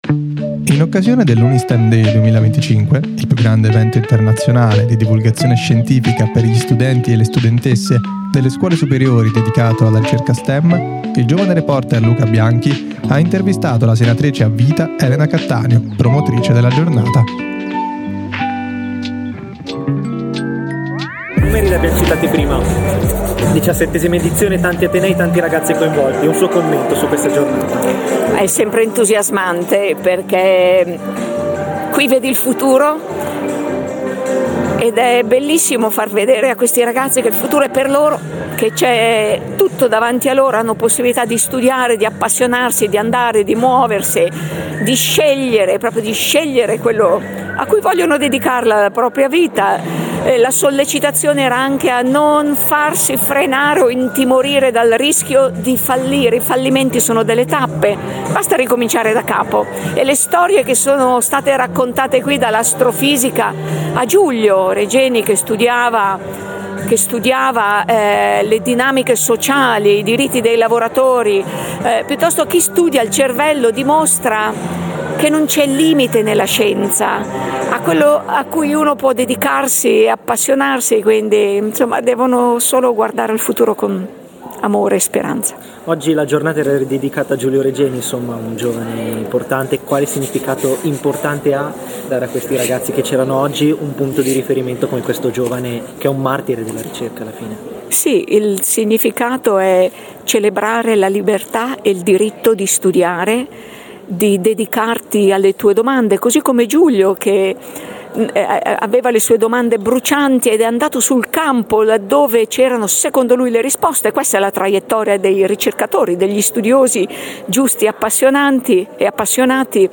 Intervista alla senatrice a vita Elena Cattaneo